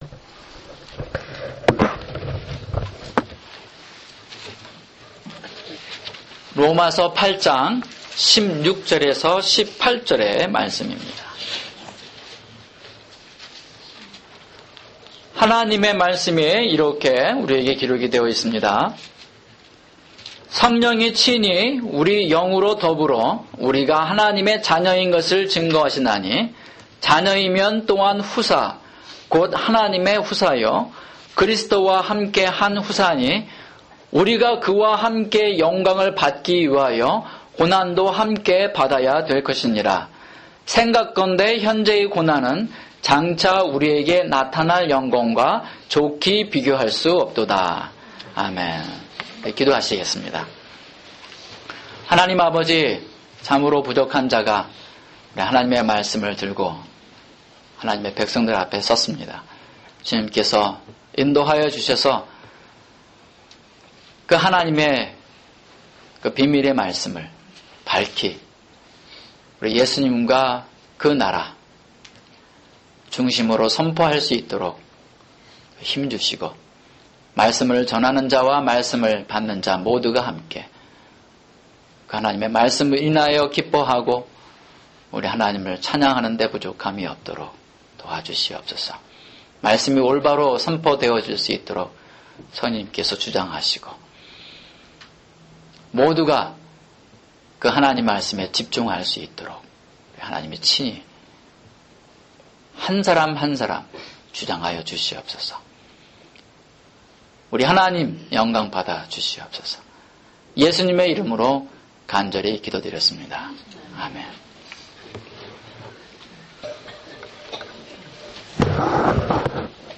[주일 설교] 로마서 8:16-18